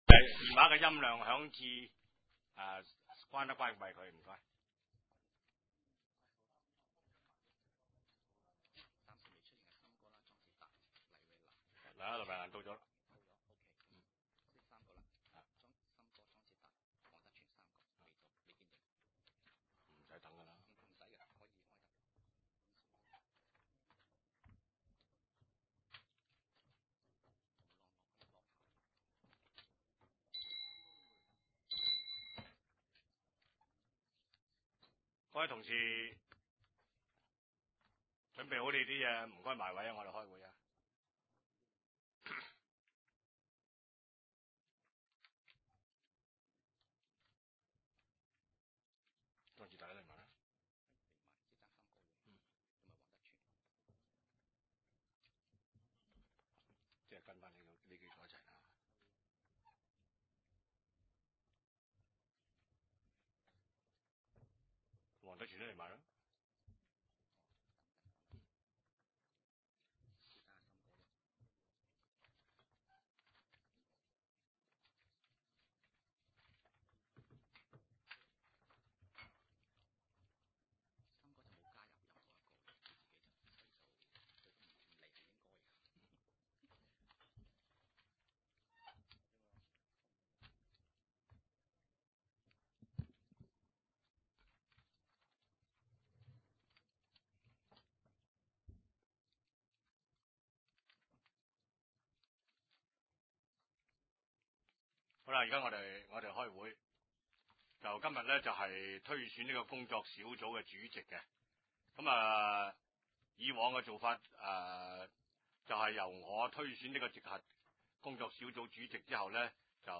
二零零八年一月二十四日 第三届深水埗区议会 地区设施委员会特别会议议程 日期：二零零八年一月二十四日 ( 星期四 ) 时间：下午二时三十分 地点：九龙长沙湾道 303 号长沙湾政府合署 4 字楼 深水埗区议会会议室 议 程 讨论时间 1.